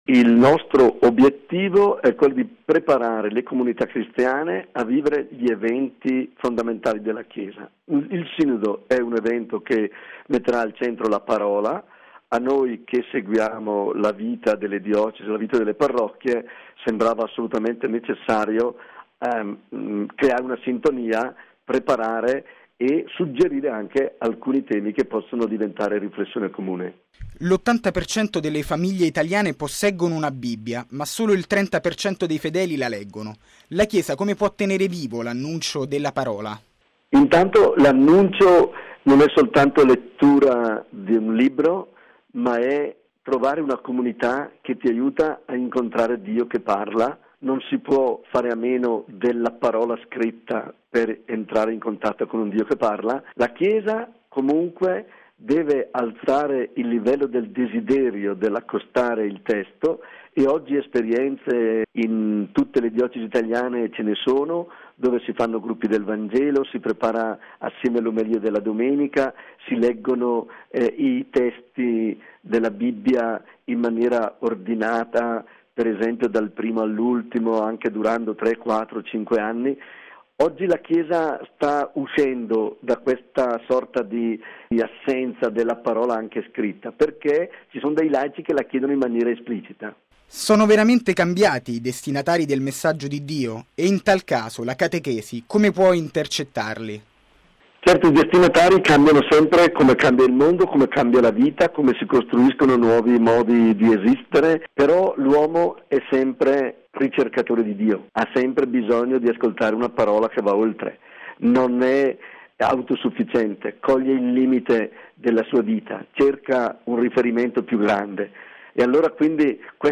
Chiude a Trevi la Settimana di aggiornamento pastorale sui temi della Sacra Scrittura nella comunità cristiana. Intervista con mons. Domenico Sigalini